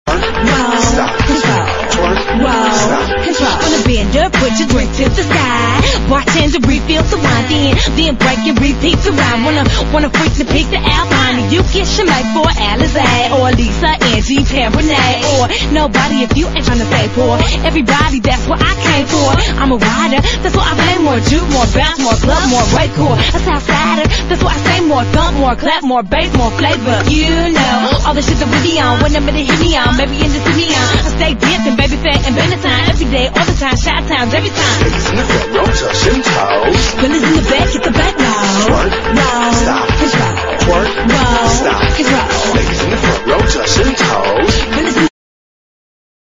# ELECTRO
まさにゲットー・エレクトロなサウンド！